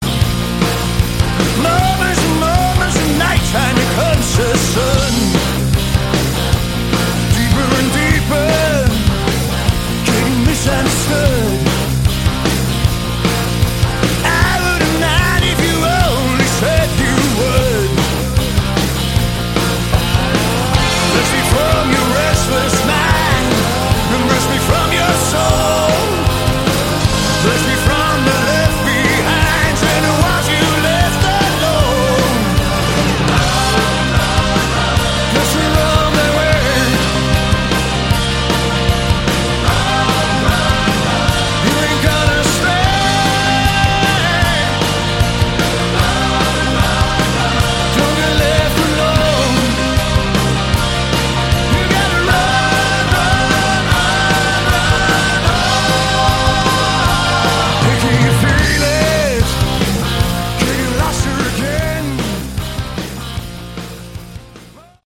Category: Hard Rock
lead guitars, vocals
bass
keyboards, backing vocals
drums